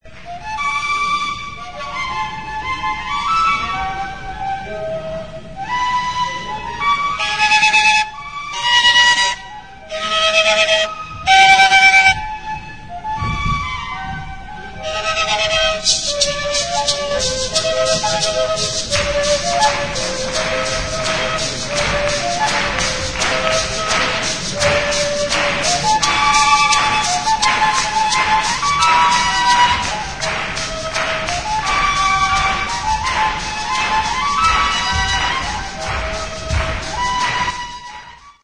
Aerophones -> Flutes -> Fipple flutes (one-handed)
AMERICA -> KOLONBIA
GAITA MACHO; CUISI BUNZI MACHO
Bi zuloko flauta zuzena.